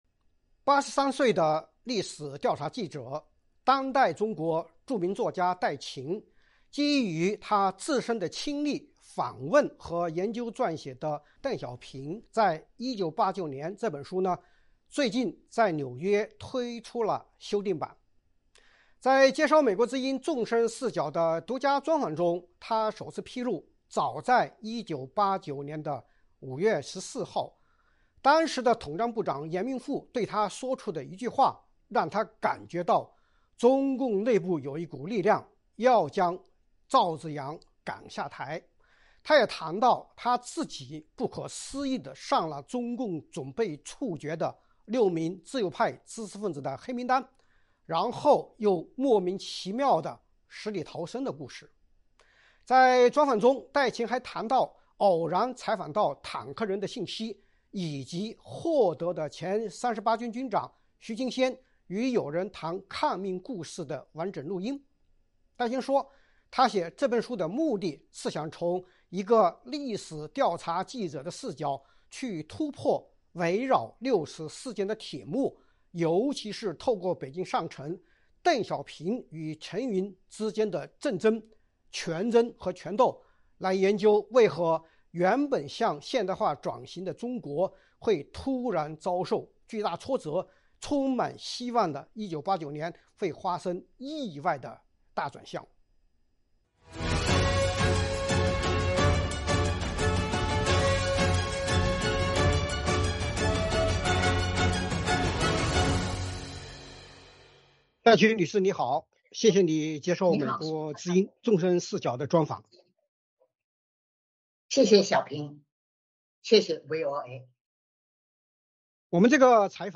专访戴晴 ：六四事件铁幕背后：邓小平陈云权力斗争